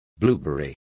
Complete with pronunciation of the translation of blueberry.